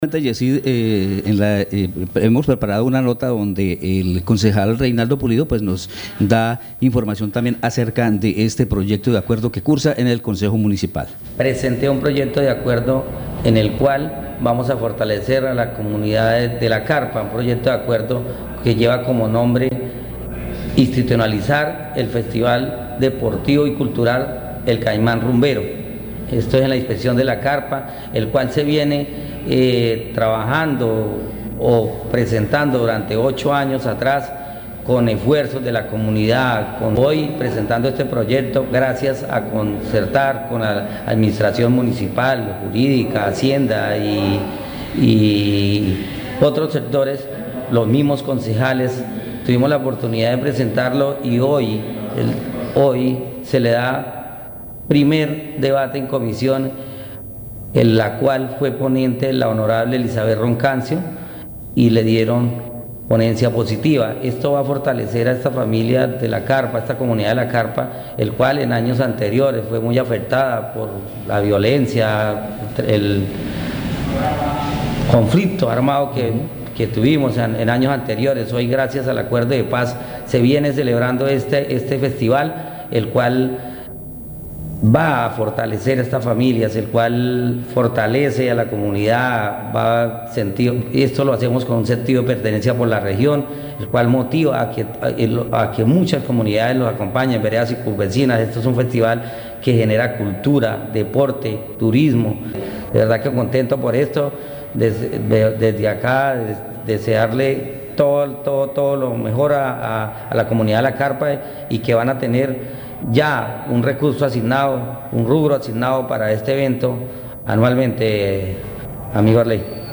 Escuche a Reinaldo Puliso, concejal de San José del Guaviare.